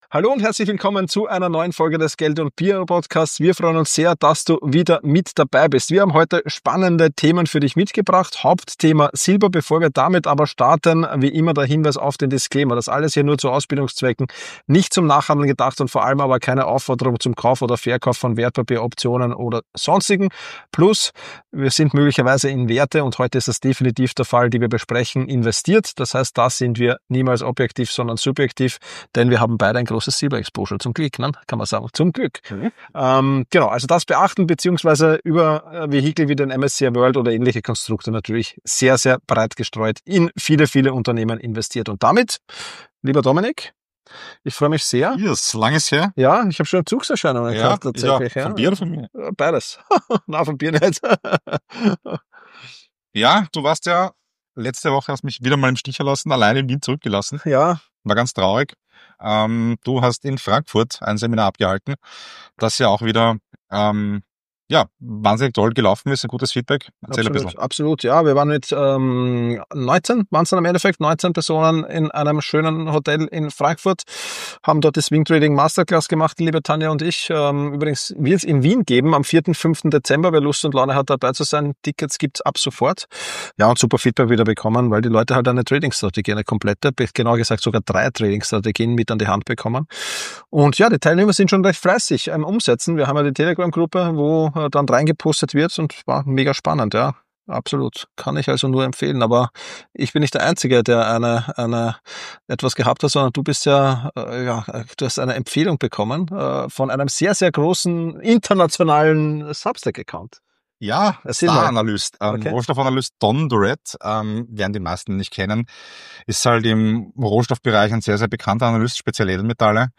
In dieser Episode dreht sich alles um das Thema Silber – die beiden Hosts besprechen aktuelle Entwicklungen am Silbermarkt, sprechen offen über ihre eigenen Investments und analysieren spannende Trading-Strategien.